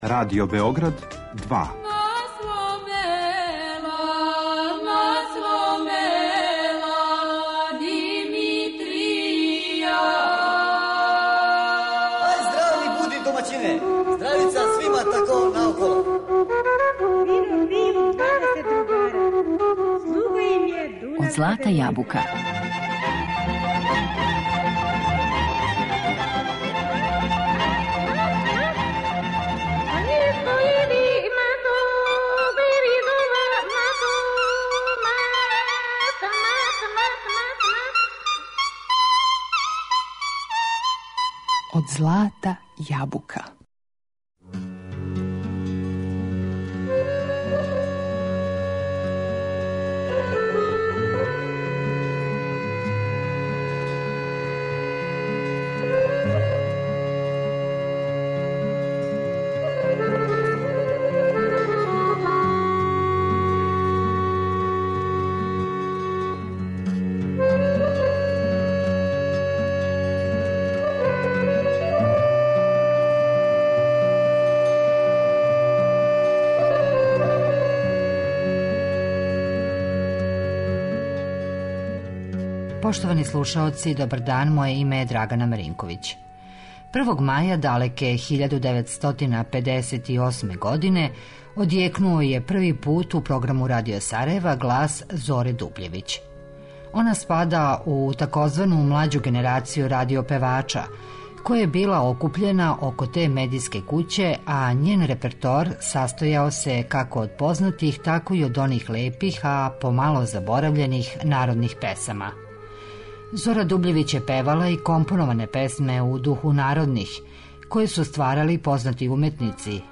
Postala je solista Radio Sarajeva daleke 1958. godine, a njen repertoar sastojao se od poznatih, lepih i zaboravljenih narodnih pesama.